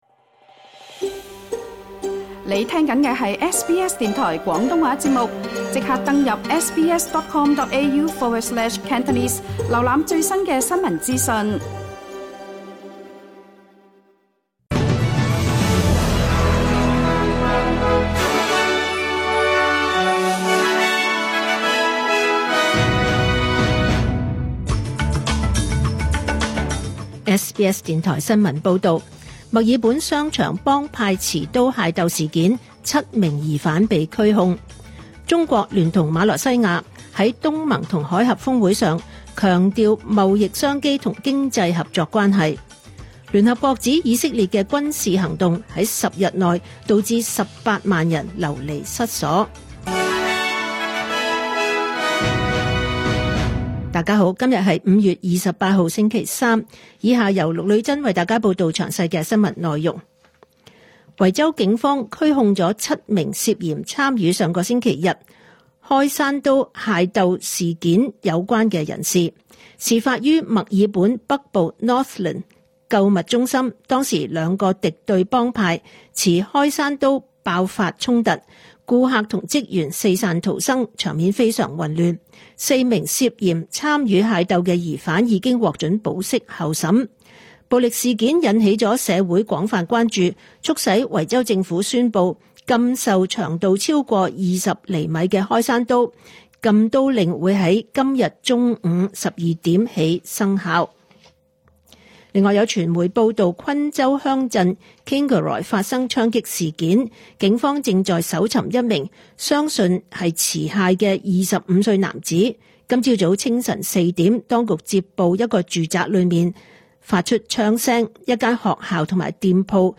2025 年 5 月 28 日 SBS 廣東話節目詳盡早晨新聞報道。